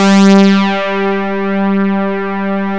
MOOG #5  G4.wav